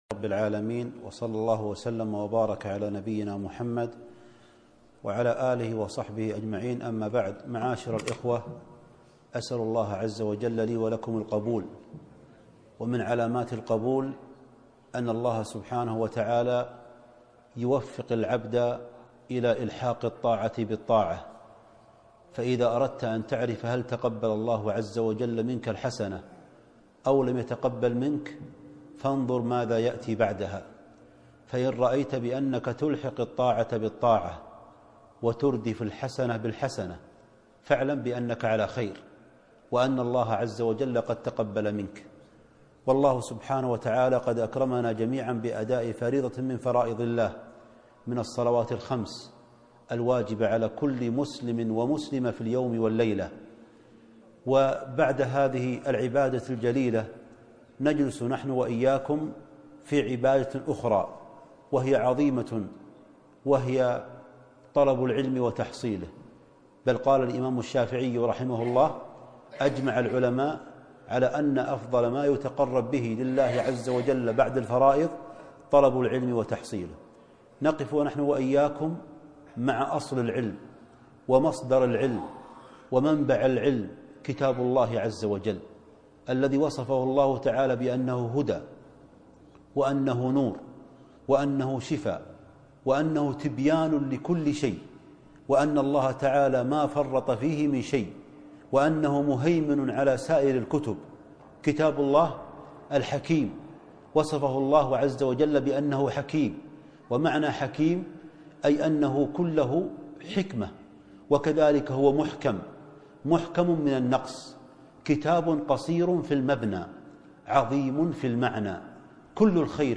أسباب الفوز والفلاح - كلمة